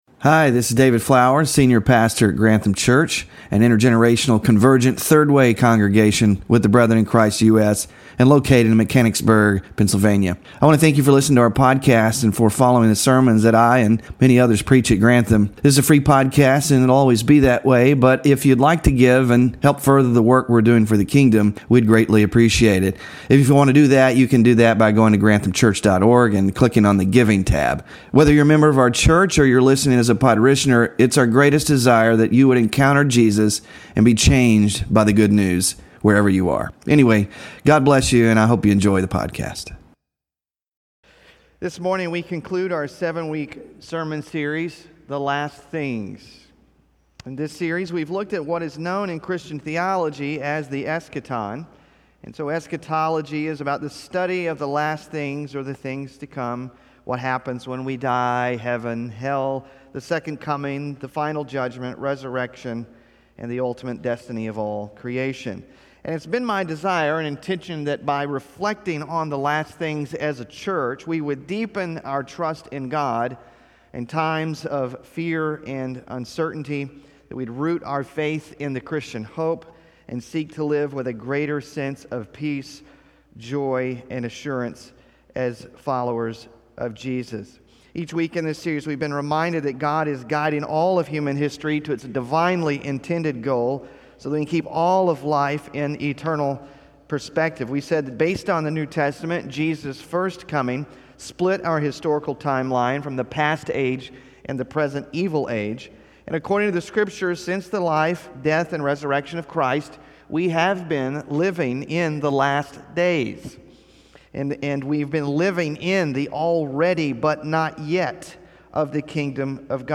WORSHIP RESOURCES LIVING IN LIGHT OF THE LAST THINGS – SERMON SLIDES 7 of 7 (6-15-25) SMALL GROUP QUESTIONS 7 of 7 (6-15-25) BULLETIN (6-15-25) N.T. WRIGHT – A NEW HEAVEN & A NEW EARTH?